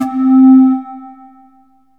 SYNTH GENERAL-1 0011.wav